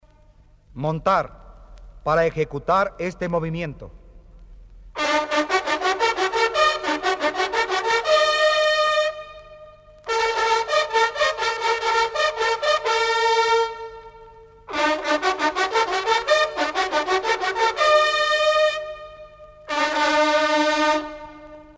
TOQUES MILITARES CON TROMPETA PARA EL ARMA DE CABALLERIA
montar.mp3